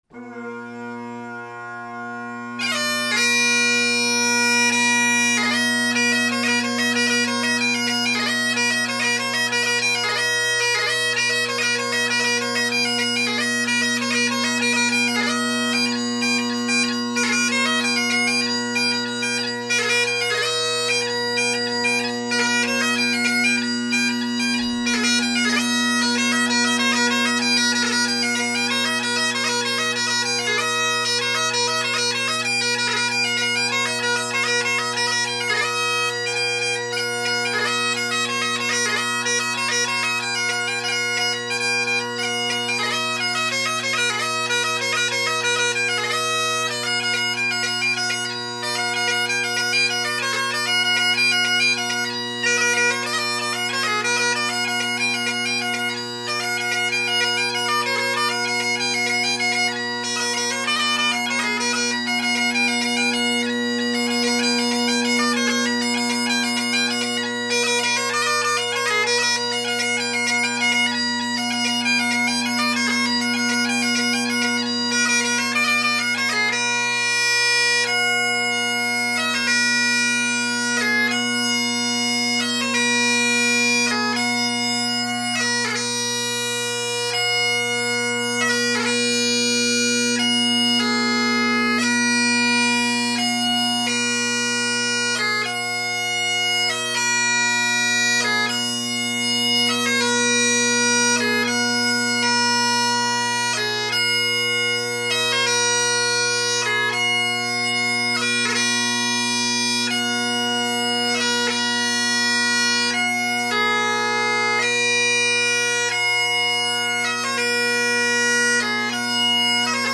RJM solo blackwood chanter, tape only on E and high G, tuning spot on at 480 Hz with a day old Troy McAllister chanter reed that was artificially broke in with a bit of spit and a little squeeze at the tips to ease the reed and even less around the sound box to bring the pitch of the middle notes (C# and F#) up a tad.